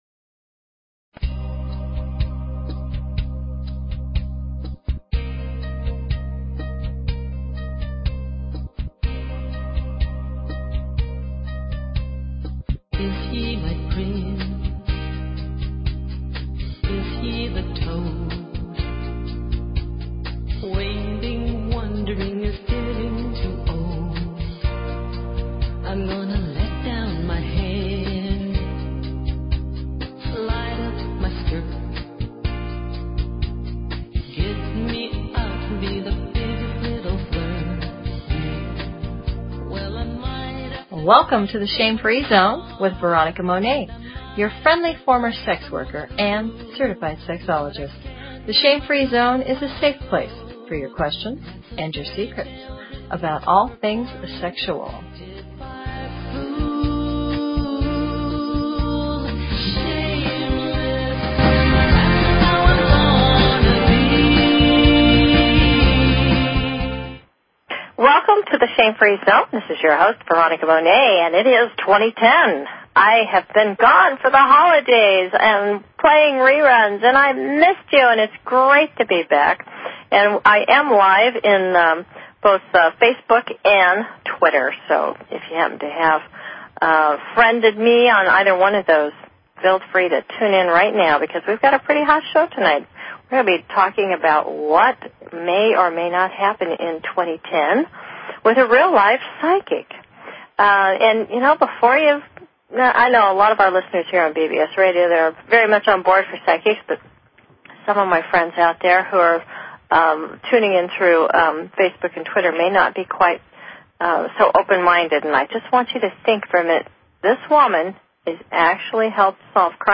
Talk Show Episode, Audio Podcast, The_Shame_Free_Zone and Courtesy of BBS Radio on , show guests , about , categorized as